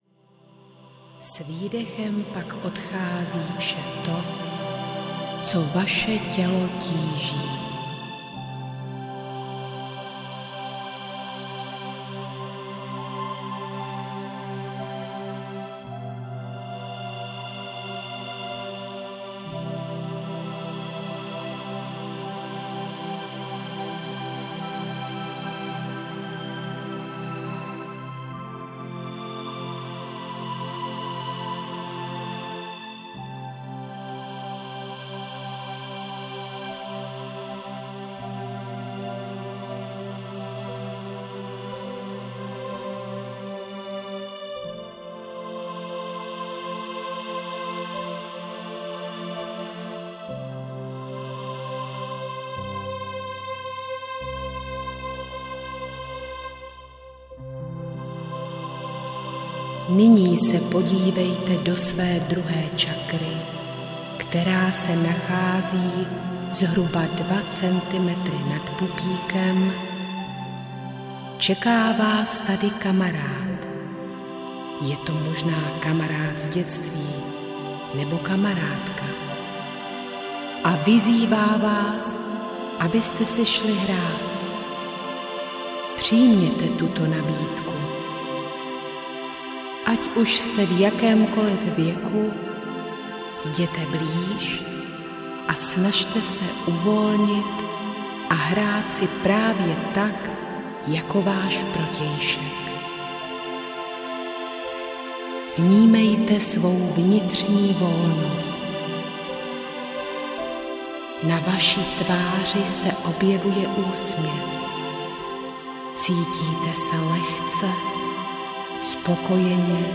Hubneme s Křídly audiokniha
Ukázka z knihy